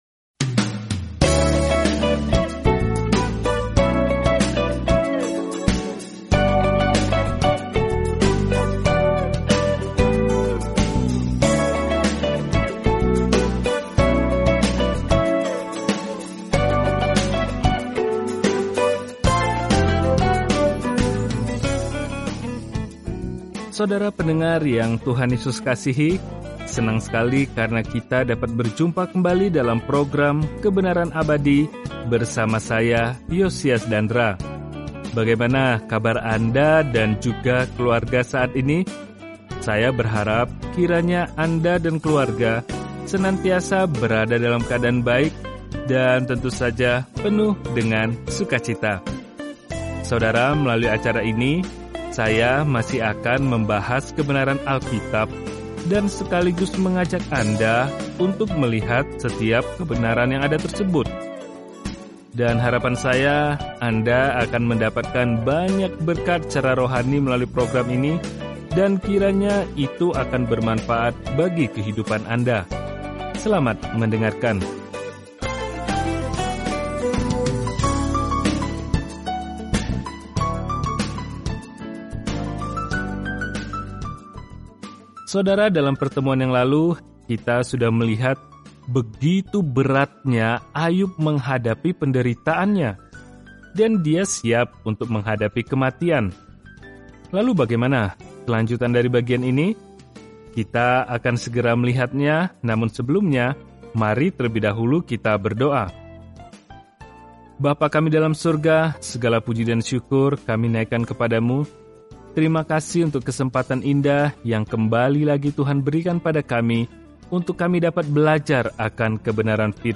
Jelajahi Galatia setiap hari sambil mendengarkan pelajaran audio dan membaca ayat-ayat tertentu dari firman Tuhan.